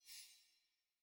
sfx-pm-level-select-unlocked-hover.ogg